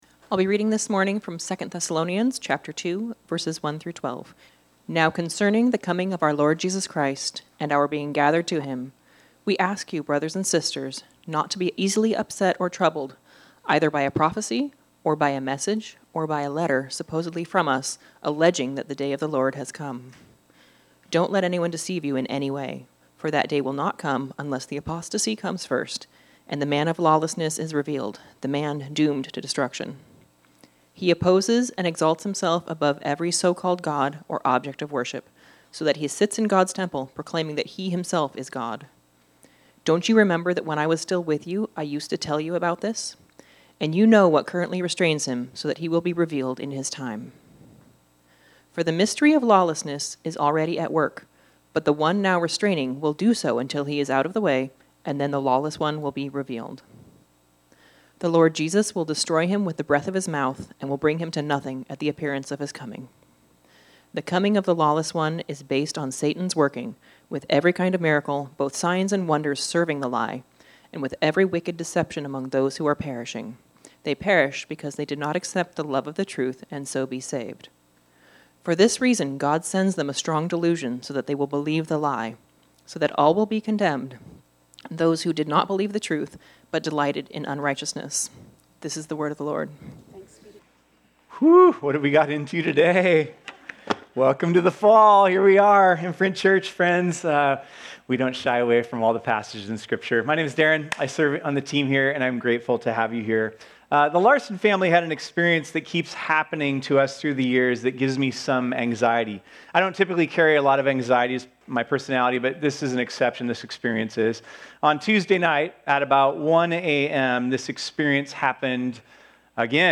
This sermon was originally preached on Sunday, September 7, 2025.